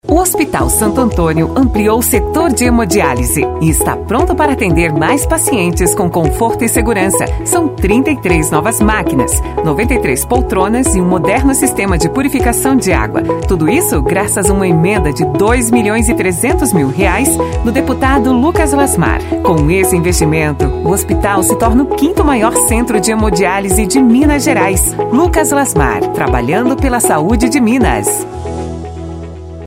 Boletim de Rádio